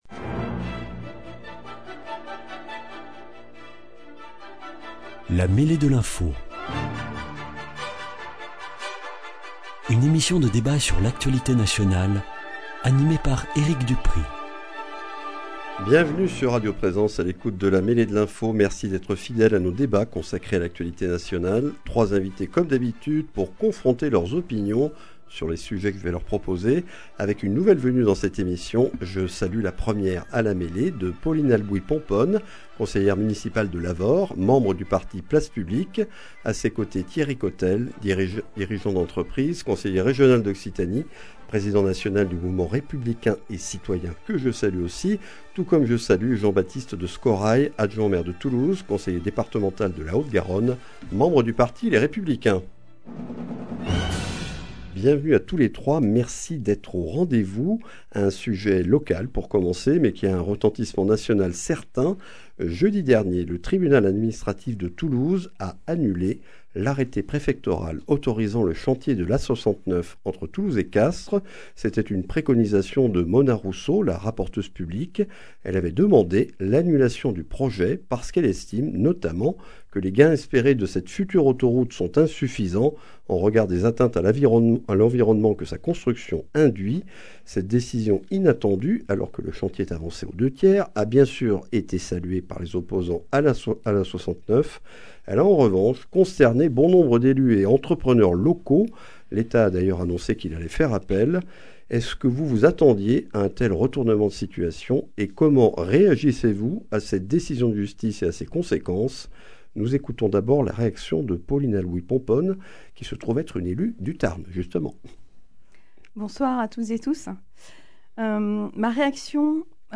Au sommaire de ce numéro, l’annulation de l’arrêté préfectoral autorisant le chantier de l’A69 et l’idée d’E. Macron d’élargir aux autres pays de l’UE la dissuasion nucléaire française. Avec Pauline Albouy Pomponne, conseillère municipale de Lavaur, membre du parti Place Publique ; Thierry Cotelle, conseiller régional d’Occitanie, président national du Mouvement Républicain et Citoyen, directeur d’entreprise ; Jean-Baptiste de Scorraille, conseiller départemental de la Haute-Garonne, adjoint au maire de Toulouse, conseiller communautaire de Toulouse Métropole, membre du parti Les Républicains.